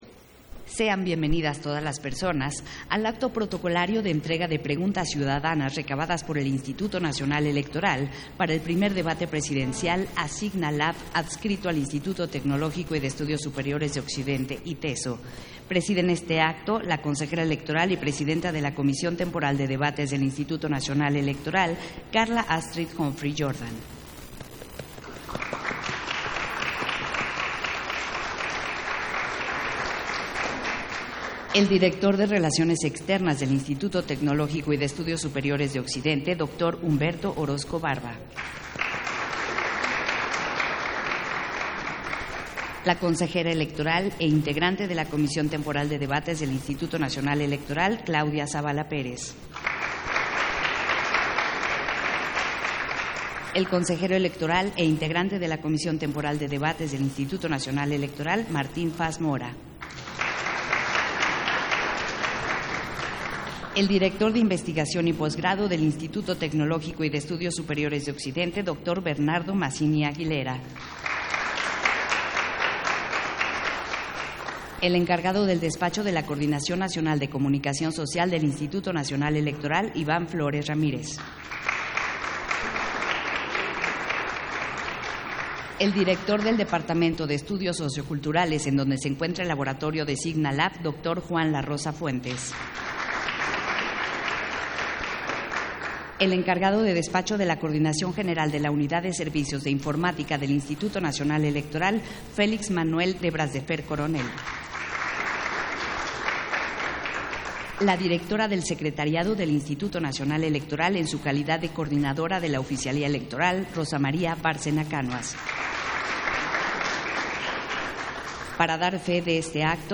Versión estenográfica del acto protocolario de entrega de preguntas ciudadanas recabadas por el INE, para el Primer Debate Presidencial a Signa Lab